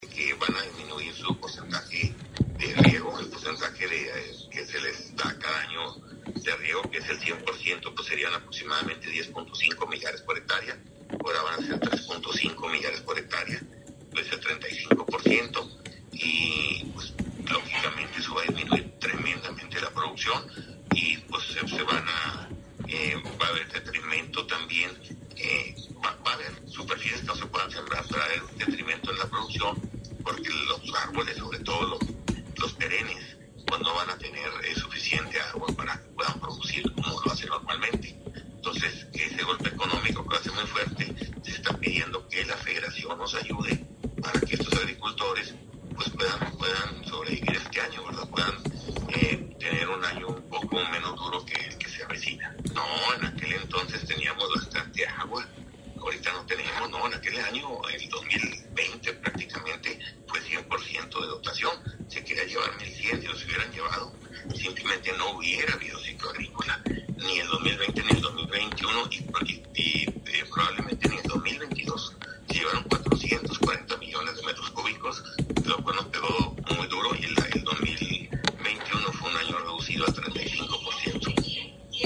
AUDIO: MARIO MATA CARRASCO, DIRECTOR EJECUTIVO DE LA JUNTA CENTRAL DE AGUA Y SANEAMIENTO (JCAS)